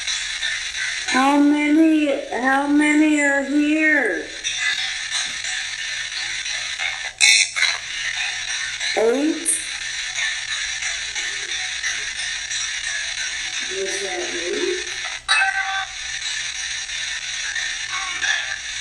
We conducted several spirit box sessions in the Portrait Room.
Recording 3 (Spirit Box)
graceland-too-spirit-box-turn-it-off-come-back.m4a